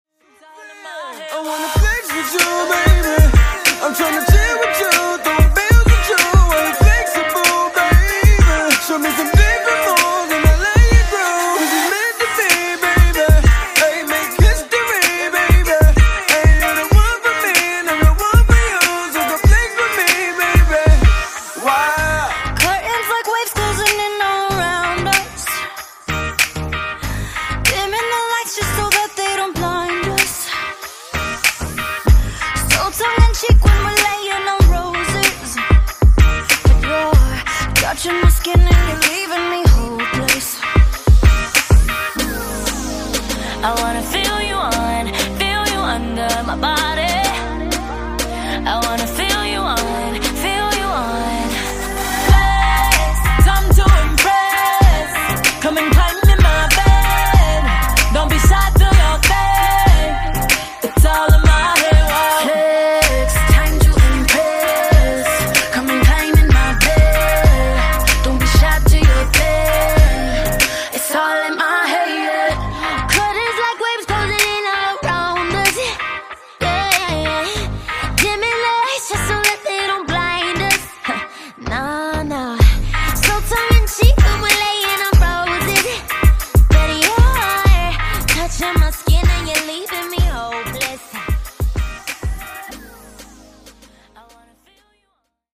Genre: RE-DRUM Version: Clean BPM: 120 Time